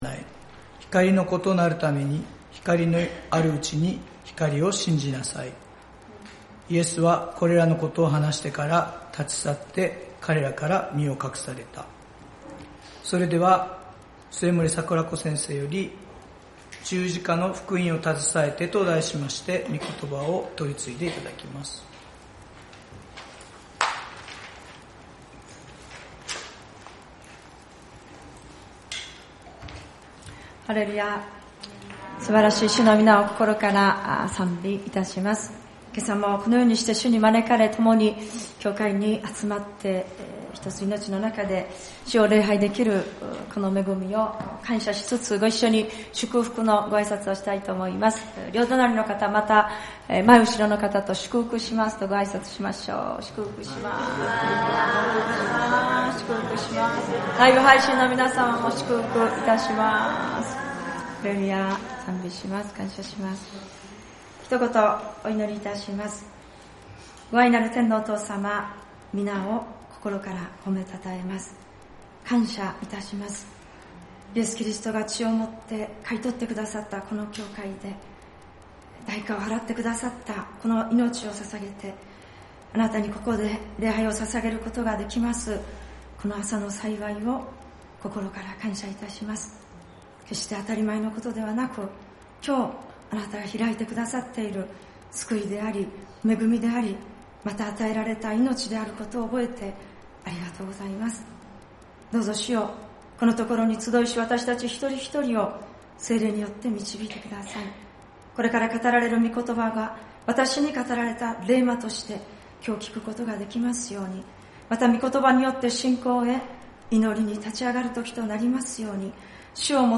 聖日礼拝「十字架の福音を携えて」ヨハネによる福音書 12:35-36